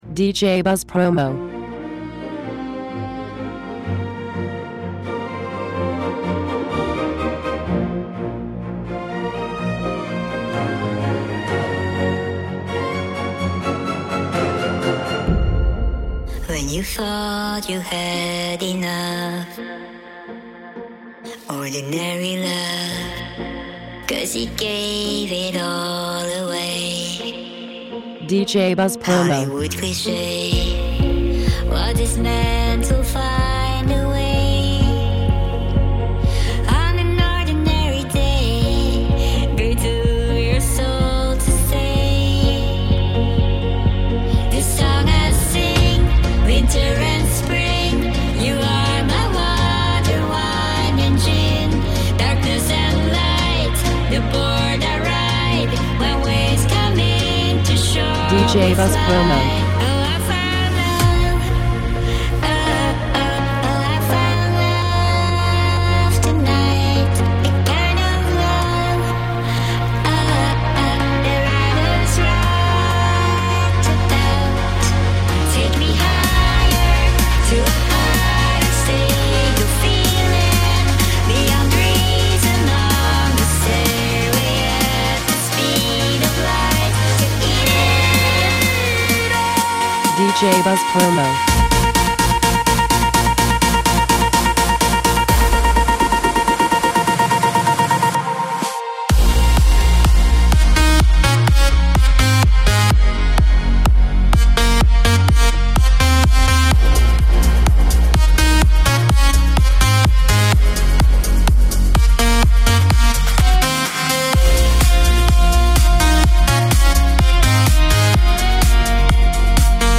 Epic Remix!